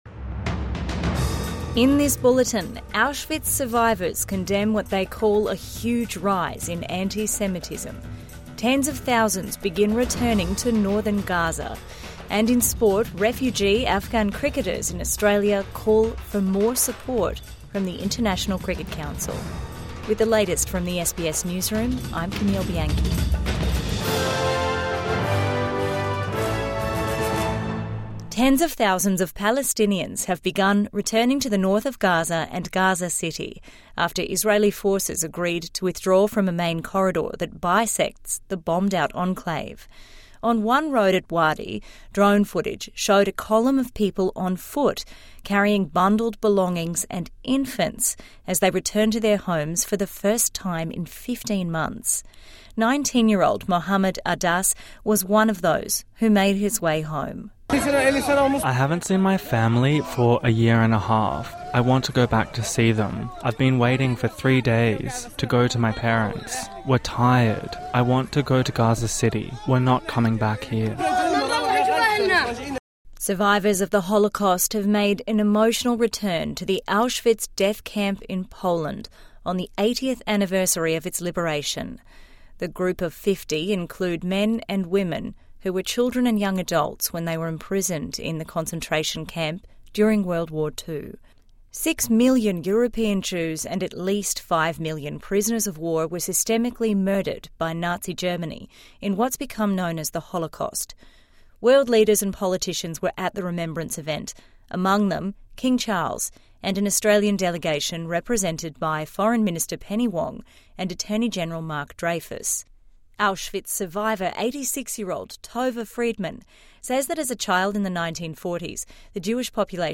Morning News Bulletin 28 January 2025